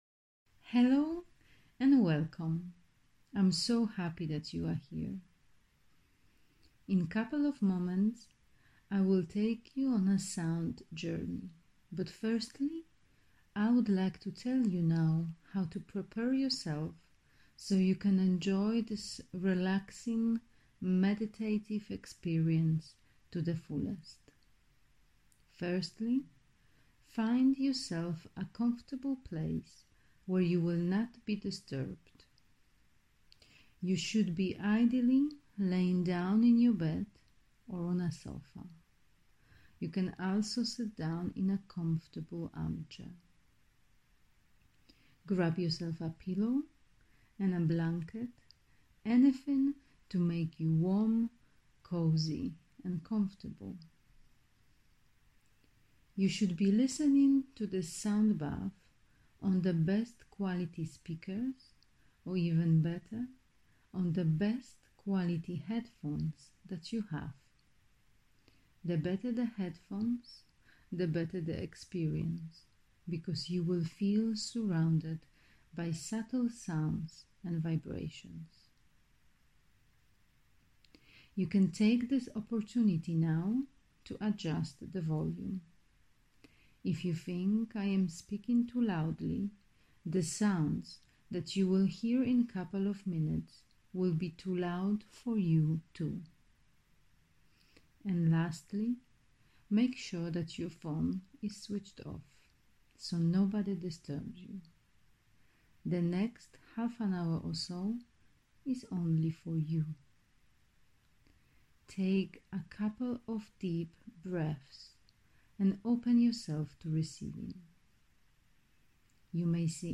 I created this Sound Bath because I simply needed to feel loved.
My cup was empty, and I decided to fill it up with the loving sounds, and vibrations of my Singing Bowls.